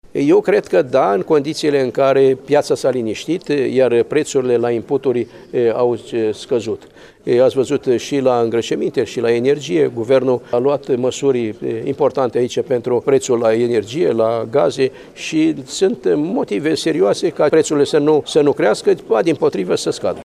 Iaşi – Ministrul Agriculturii, Petre Daea, s-a întîlnit cu fermieri şi agricultori din judeţ